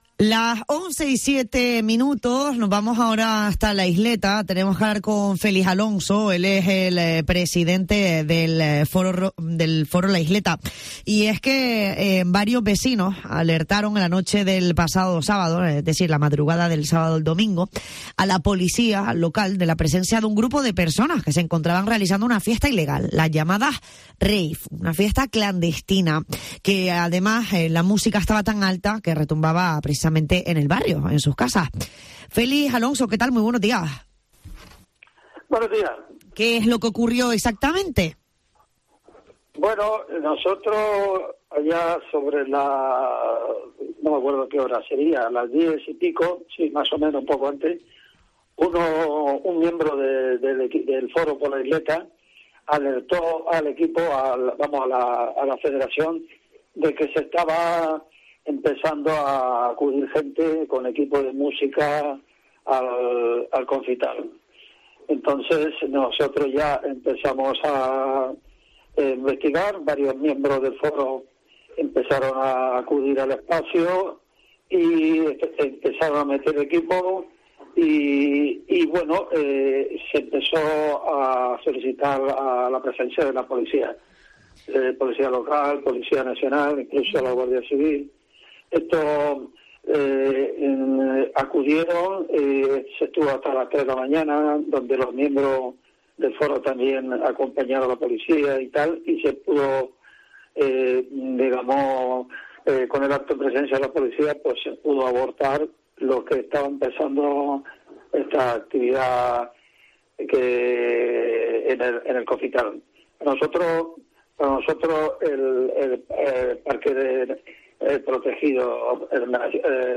En Herrera en COPE Gran Canaria hemos hablado con